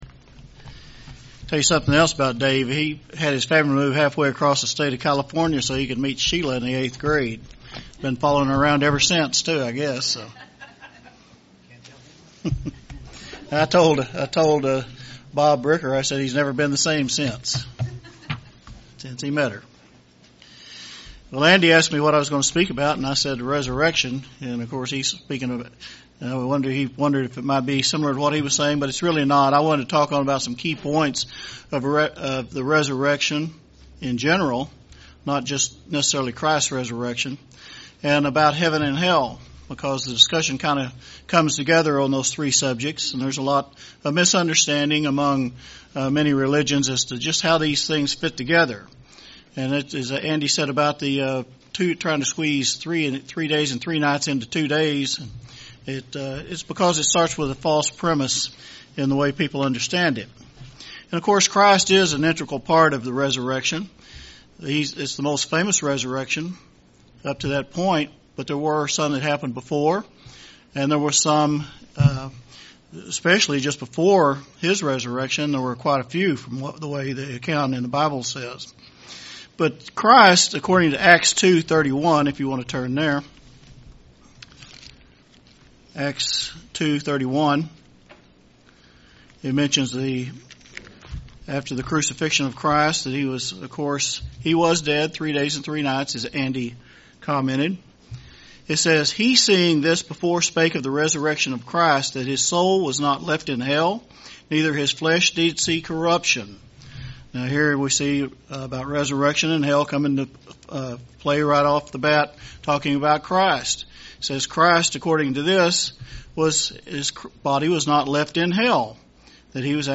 In this sermon, the speaker looks into the topics of heaven, hell, and what the bible has to say about the resurrections.